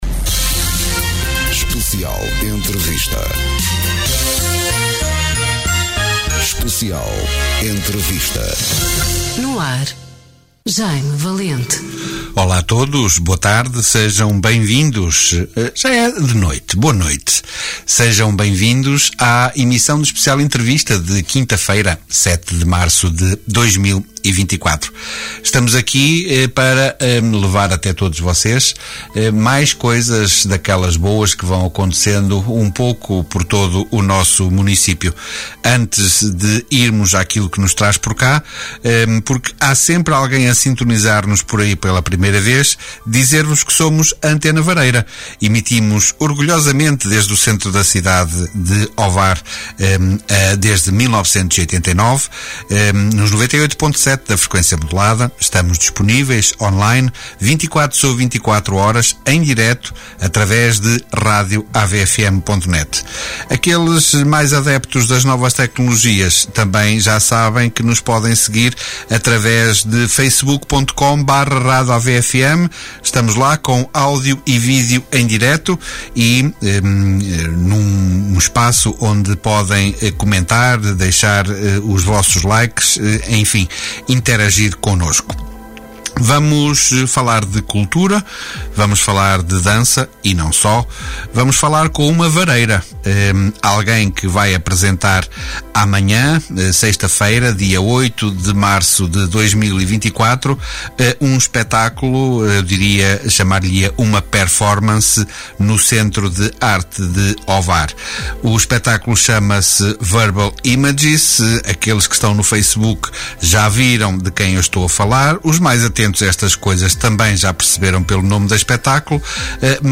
Especial Entrevista
Direitos reservados Especial Entrevista Conversas olhos nos olhos em direto Mais informações